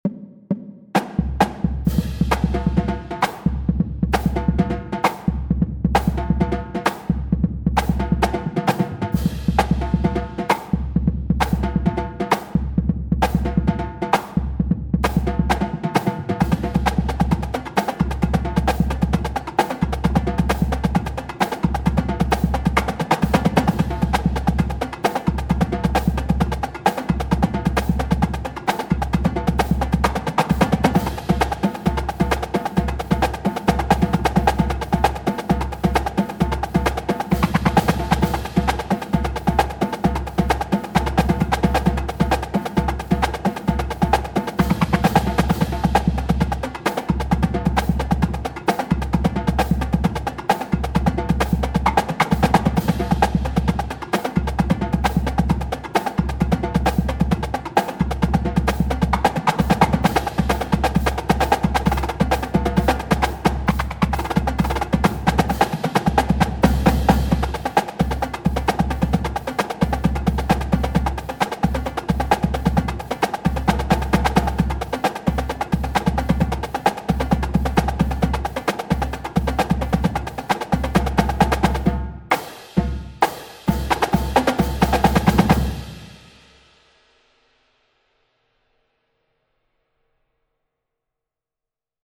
Voicing: Marching Percussion